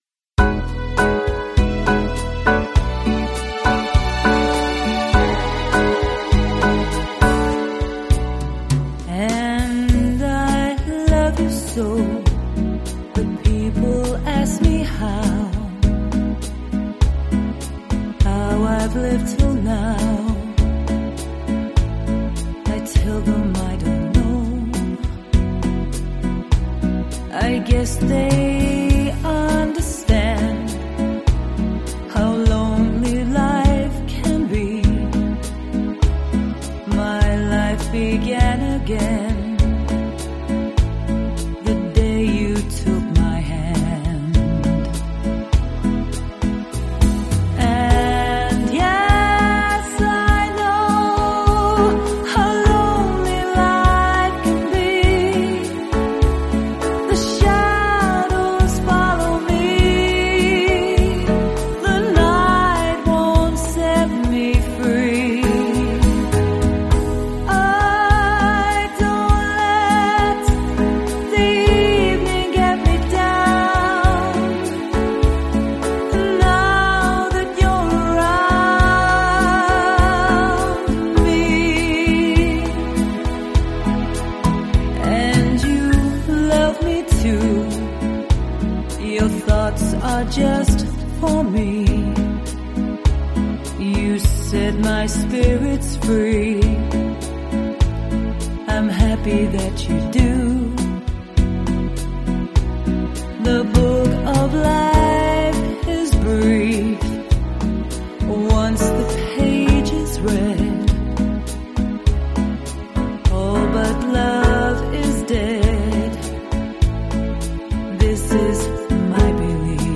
I have recently moved my gear to a different area in the house and ever since have had no joy with anything I've recorded !!
It's unmastered and not totally finished.....I gave up when I couldn't get it right !!